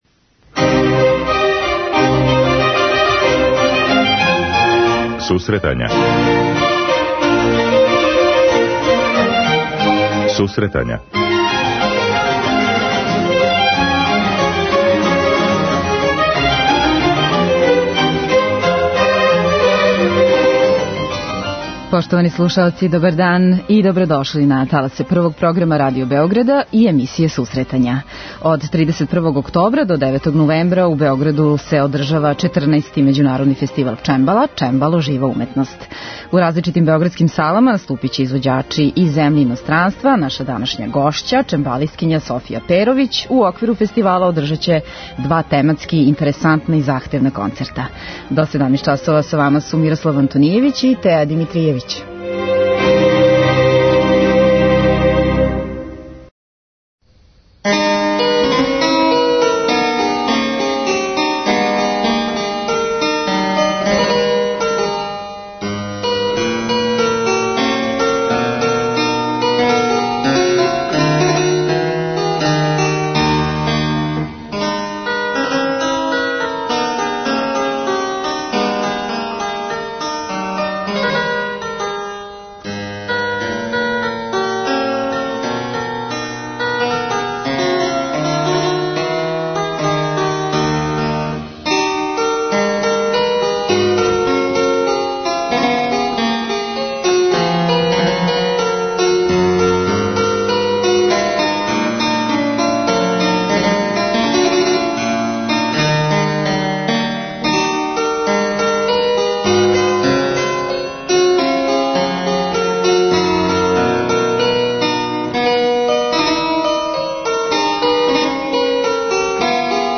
преузми : 10.79 MB Сусретања Autor: Музичка редакција Емисија за оне који воле уметничку музику.